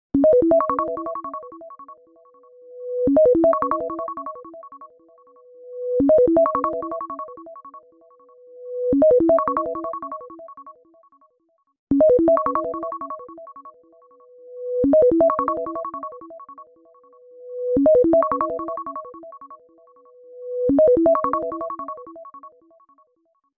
Iphone Ringtone (Instrumental)
Violin Instrumental